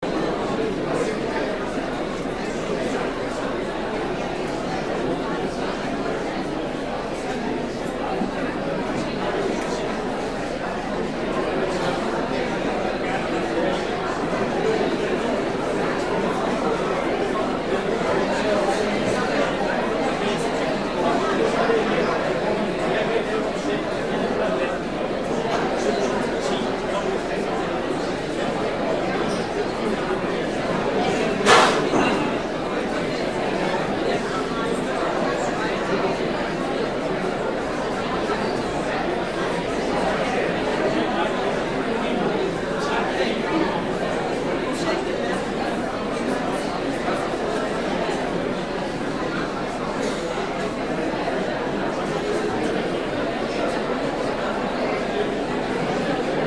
Download Disney Audience sound effect for free.
Disney Audience